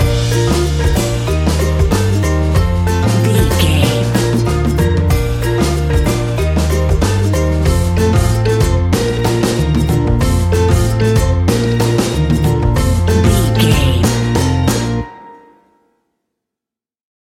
A warm and fun piece of calypso reggae style fusion music.
That perfect carribean calypso sound!
Ionian/Major
B♭
steelpan
drums
bass
brass
guitar